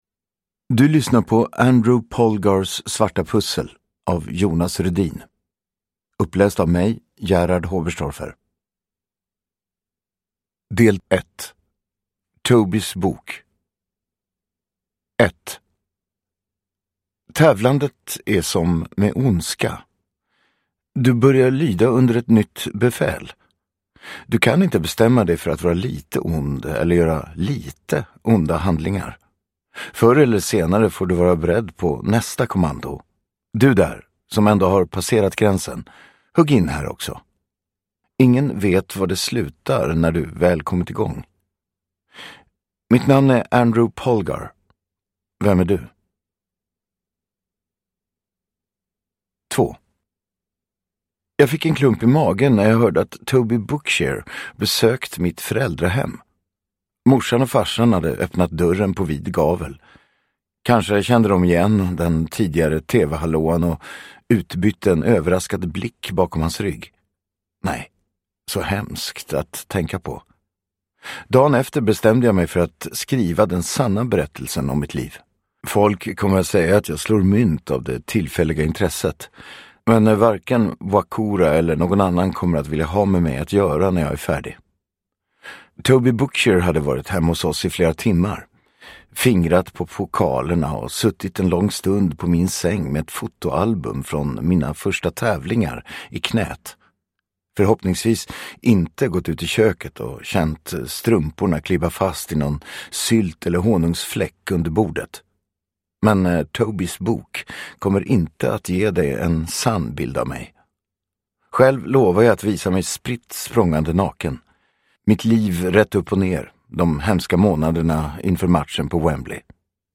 Andrew Polgars svarta pussel – Ljudbok
Uppläsare: Gerhard Hoberstorfer